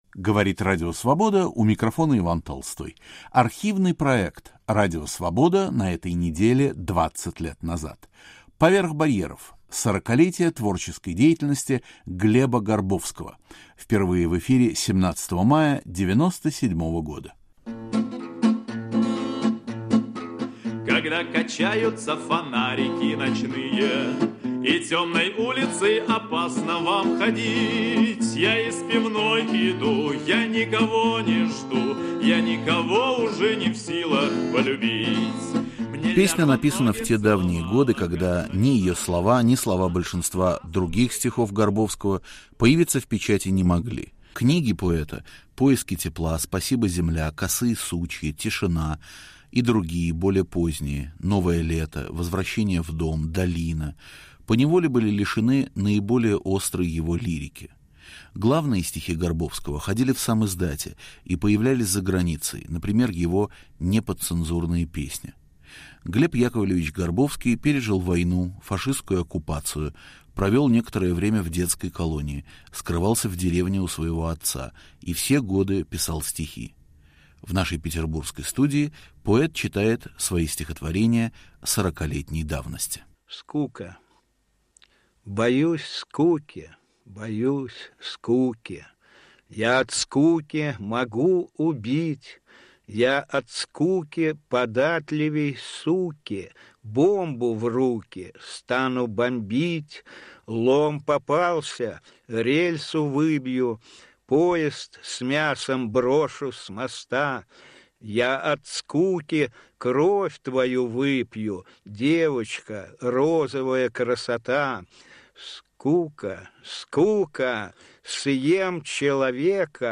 К 40-летию творчества Глеба Горбовского. В петербургской студии Радио Свобода Глеб Горбовский и его друг, литературовед Александр Панченко. Ведущий Иван Толстой. Звучат стихи в авторском исполнении и знаменитая песня на слова поэта.